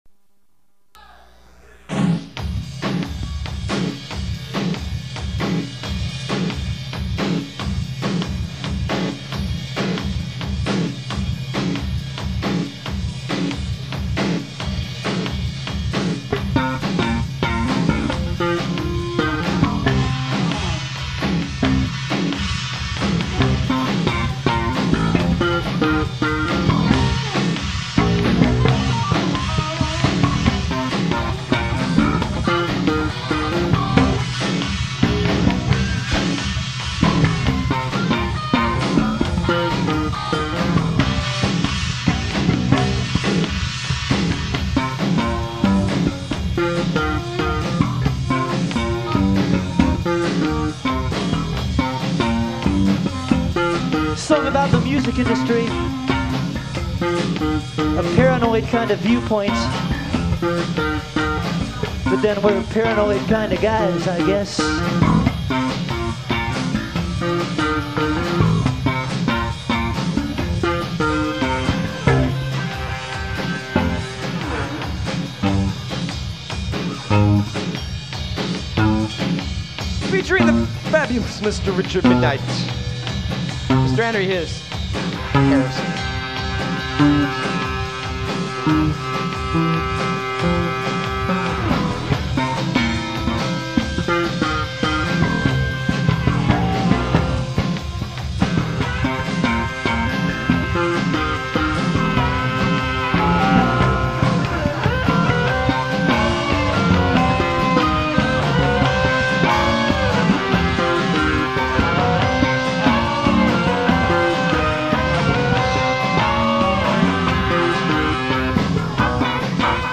Too bad it’s gone because it really sounded good there!